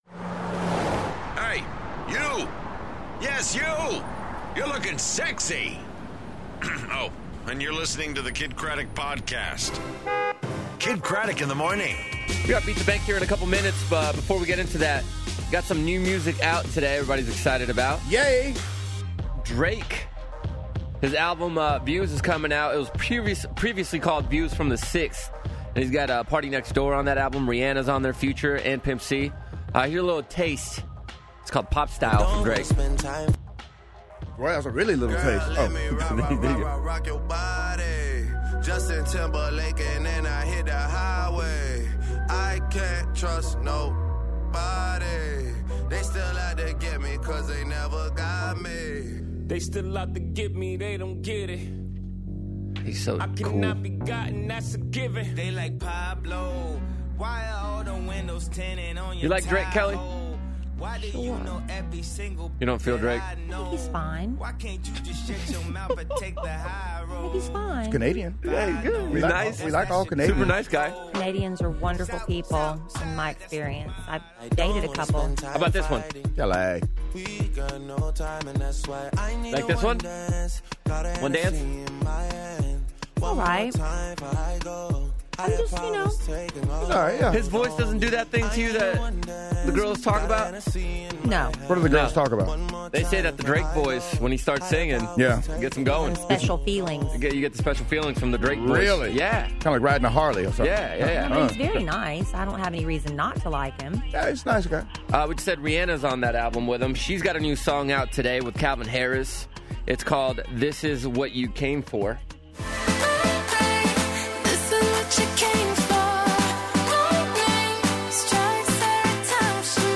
Headed To Vegas, Dalton Rappatoni In Studio For Summer Camp, And Hanging With Pauly D!